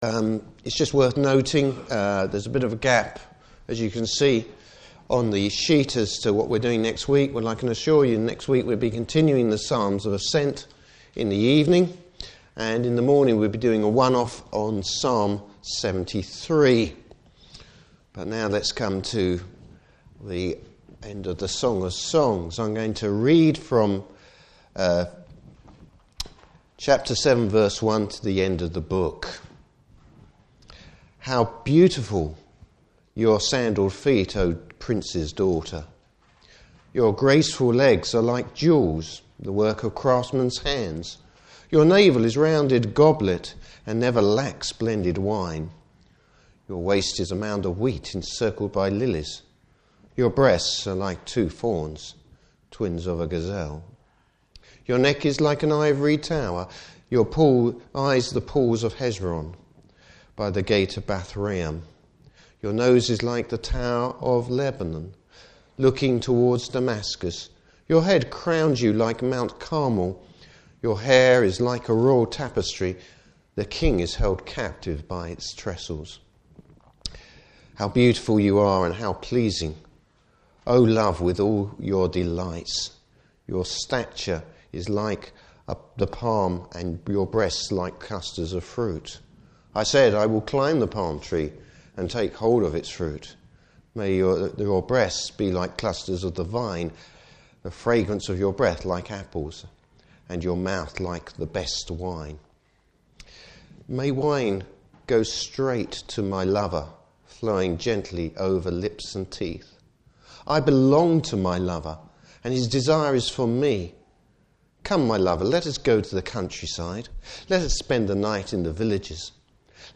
Service Type: Morning Service Bible Text: Song of Solomon 7:1-8:14.